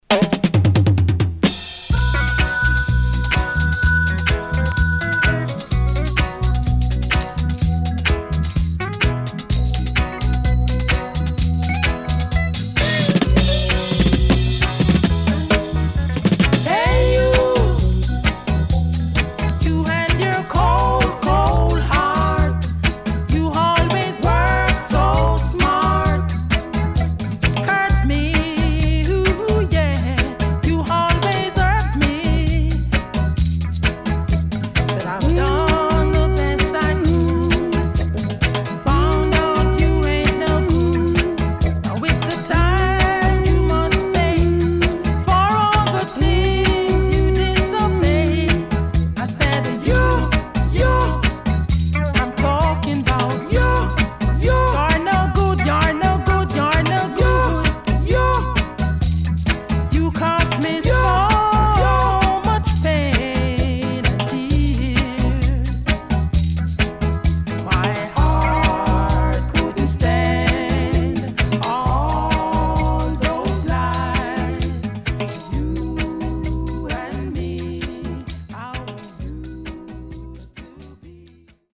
HOME > Back Order [VINTAGE DISCO45]  >  SWEET REGGAE
W-Side Good Vocal
SIDE A:所々ノイズ入ります。